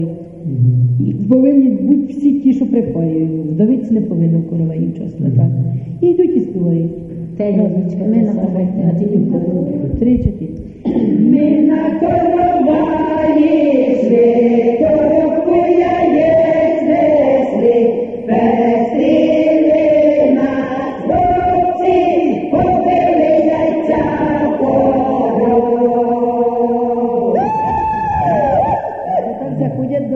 ЖанрВесільні
Місце записус. Веселий Поділ, Семенівський район, Полтавська обл., Україна, Полтавщина